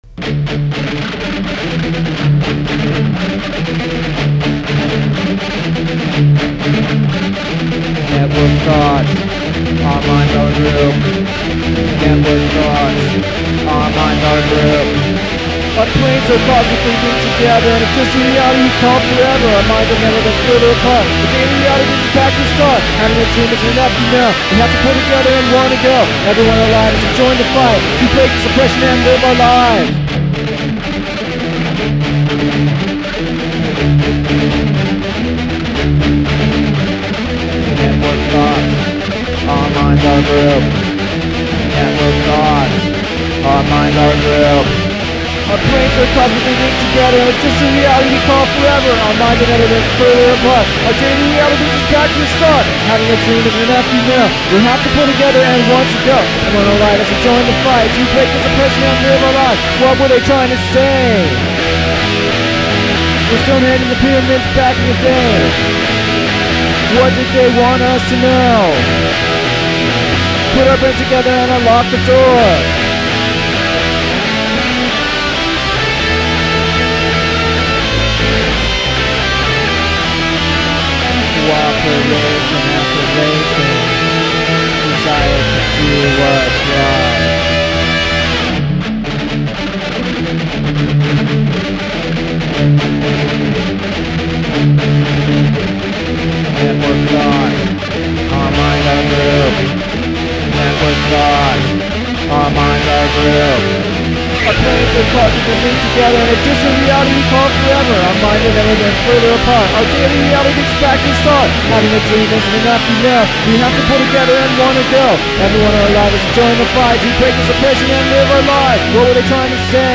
I converted some of the four track recordings to digital, with much difficulty.